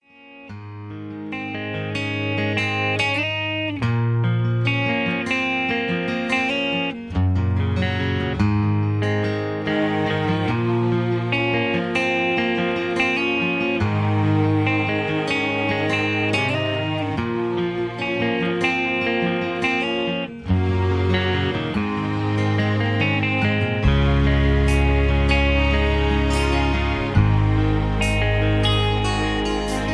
Key-Db) Karaoke MP3 Backing Tracks
Just Plain & Simply "GREAT MUSIC" (No Lyrics).